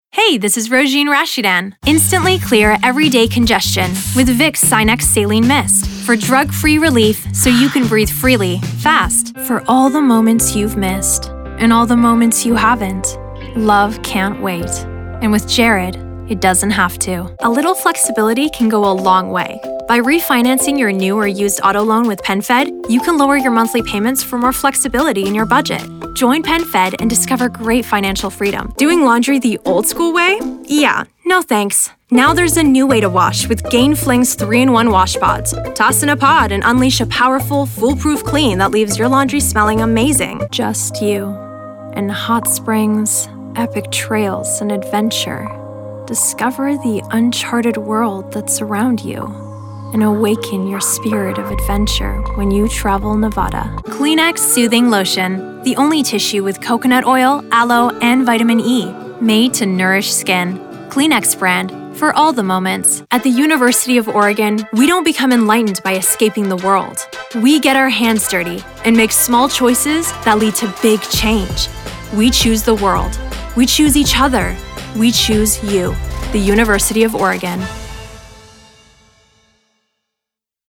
Commercial
English - USA and Canada
Voice Age
Young Adult